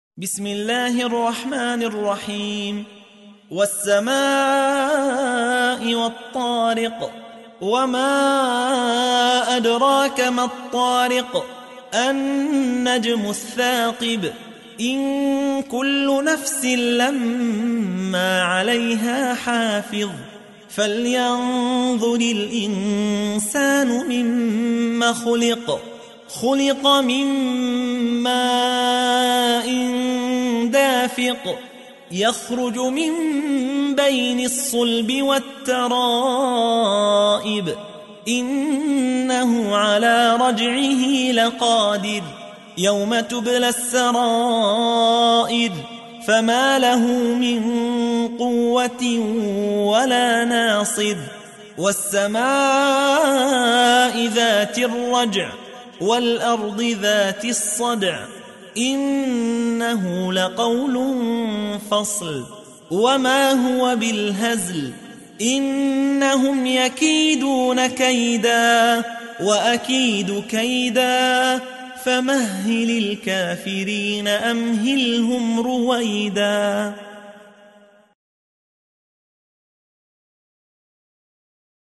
تحميل : 86. سورة الطارق / القارئ يحيى حوا / القرآن الكريم / موقع يا حسين